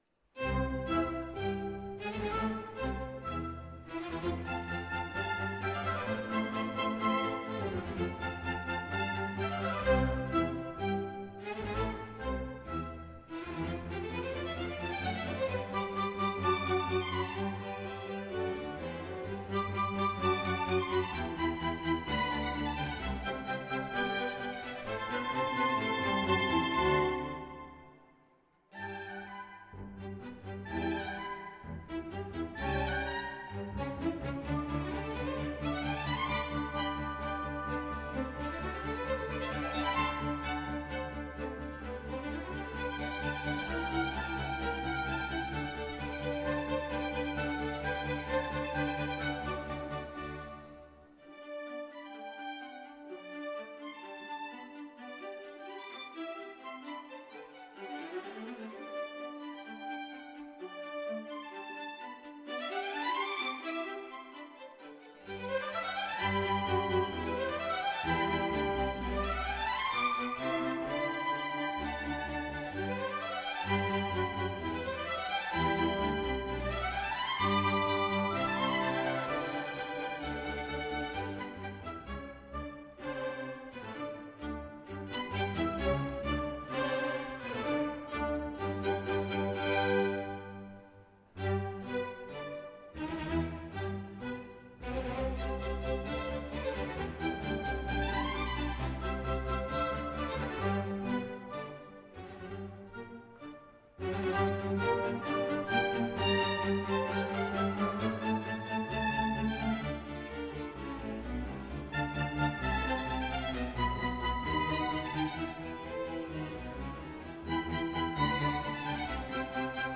Period: Classical
Genre: Symphony
• This piece is marked Allegro con spirito which means fast, lively and played with spirit and it is the first fast movement in her symphony.
• The violins at the beginning lead the melody with energy. They have lots of fast semiquavers runs, repeated notes and phrases and this gives the piece a sense of movement.
• The sounds of the harpsichord - an older sibling of the modern day piano - and the string instruments.
• You might also hear how Marianne uses repetition; melodies are repeated, and larger sections are played again.
Listen to the BBC Concert Orchestra performance of Symphony in C (mp3)